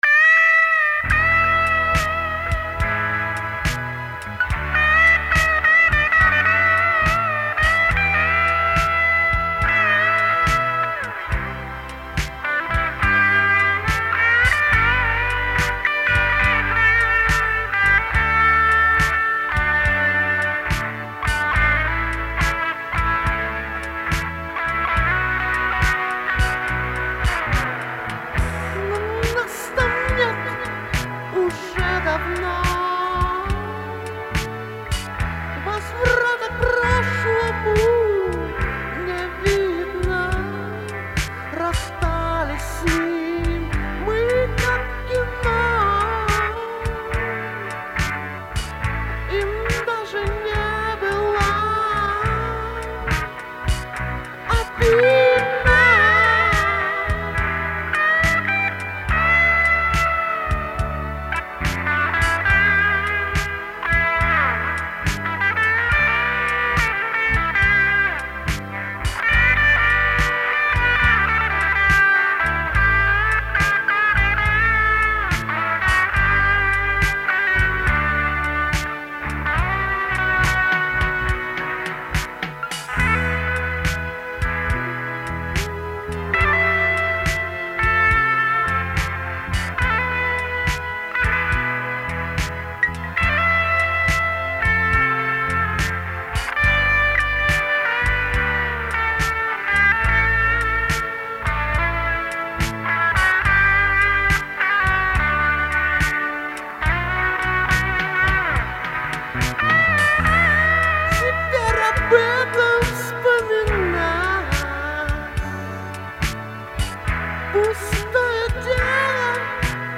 Сборник пробных записей, этюдов, репетиций
барабаны, перкуссия
клавиши
вокал (3, 4, 5, 8), бэк-вокал, бас-гитара